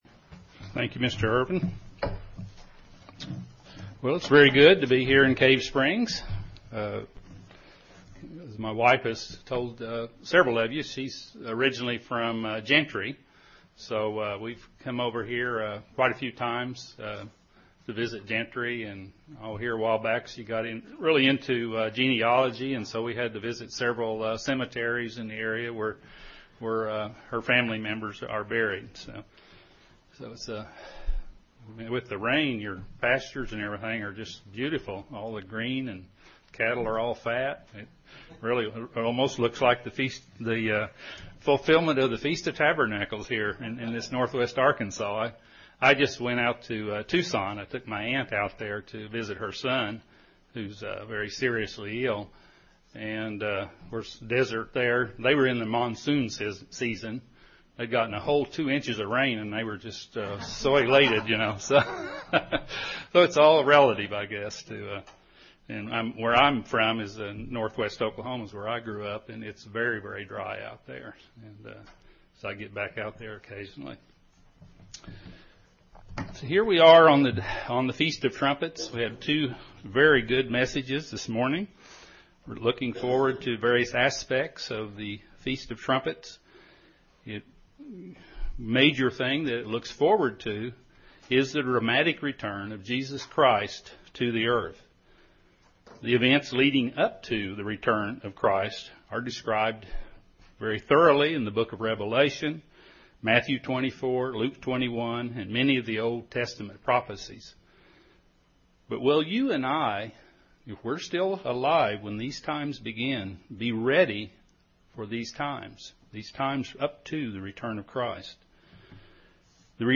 Feast of Trumpets - PM, First Message The events leading up to and including the return of Jesus Christ to earth will, from the perspective of humanity, be the most dreaded time in all of human history. Our job is to be spiritually prepared for such a time, whether these events occur in our lifetime or not.
Given in Northwest Arkansas
UCG Sermon Studying the bible?